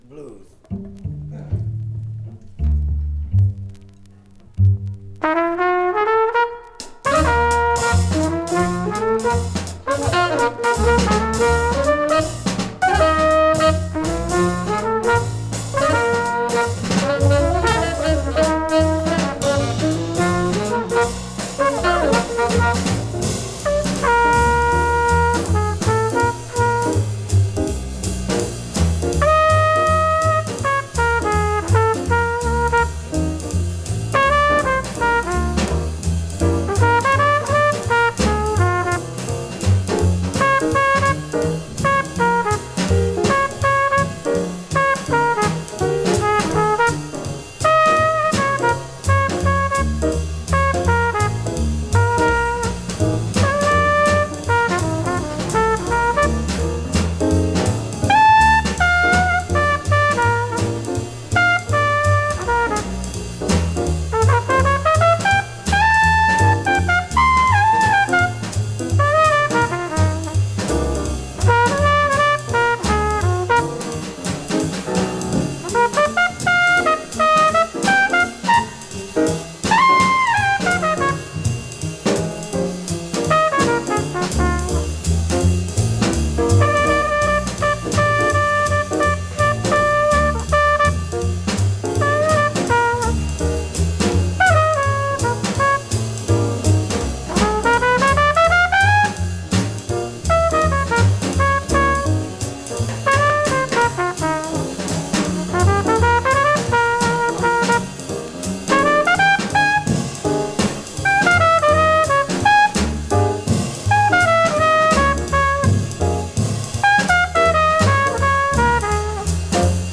trumpet
tenor sax
piano
bass
drums